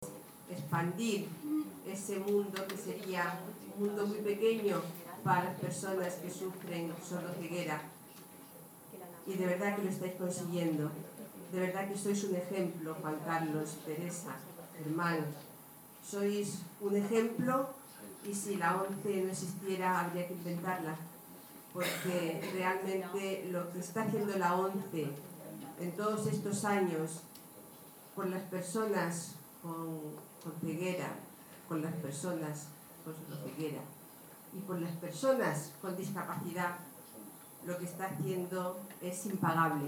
Acto en Murcia